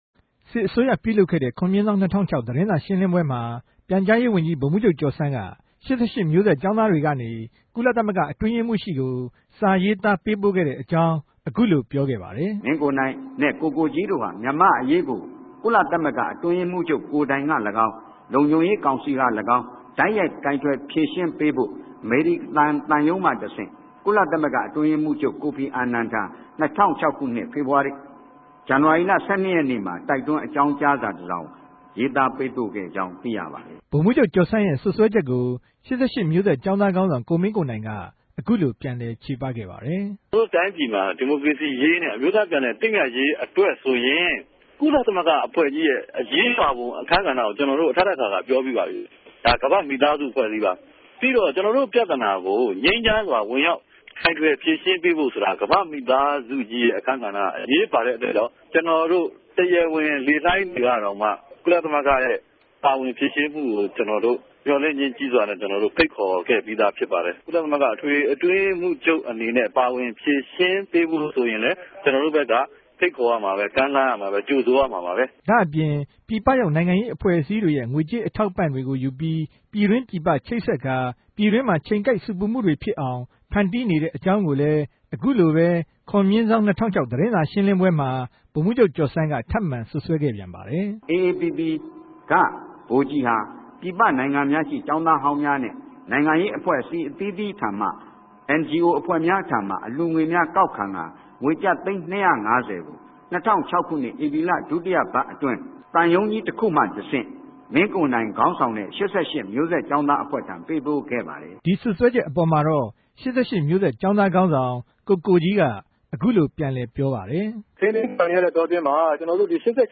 ကေဵာင်းသားခေၝင်းဆောင်တေရြဲ့ ူပန်လည် ခေဵပခဵက်တေကြို ကာယကံရြင်မဵားရဲ့ အသံအတိုင်း